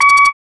Garbage Beeper 2.wav